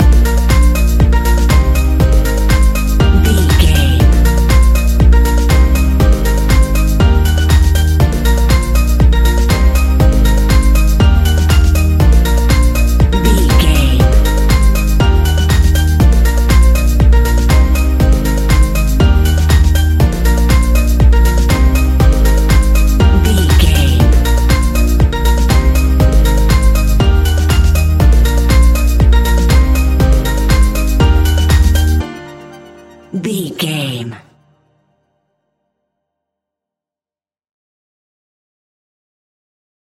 Ionian/Major
D♯
house
electro dance
synths
techno
trance